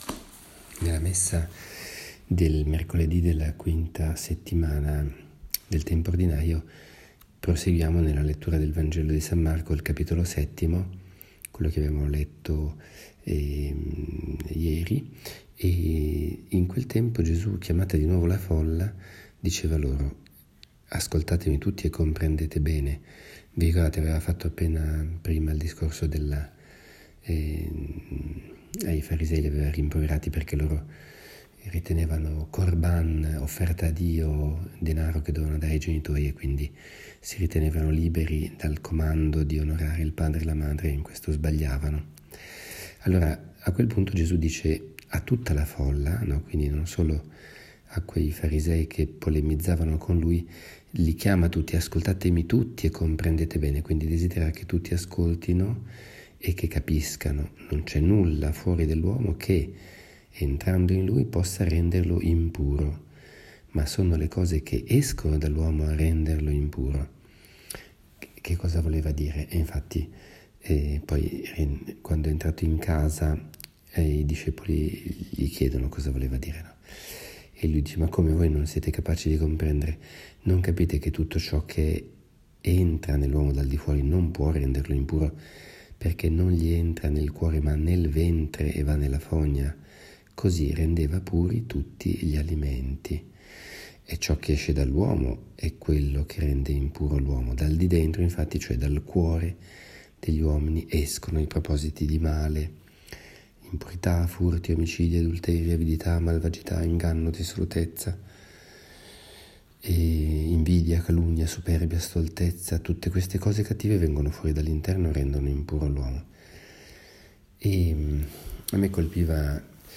Commento al vangelo (Mc 7,14-23) del 7 febbraio 2018, mercoledì della V settimana del Tempo Ordinario.